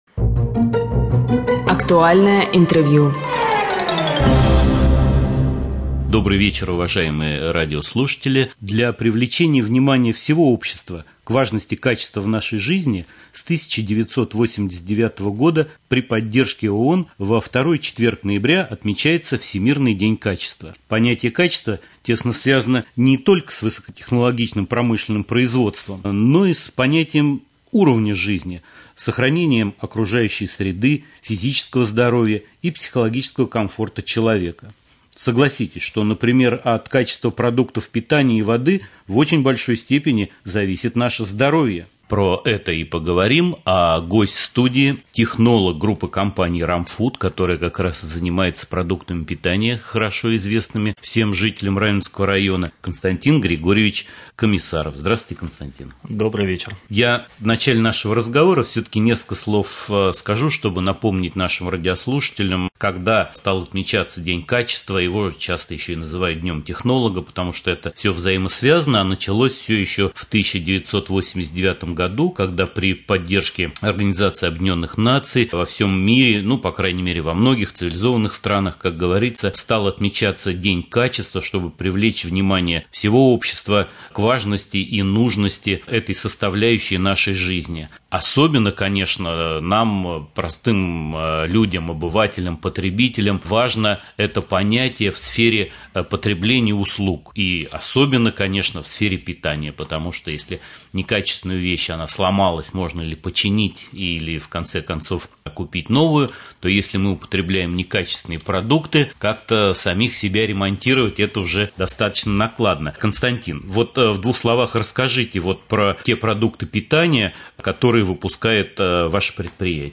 2.Актуальное-интервью.mp3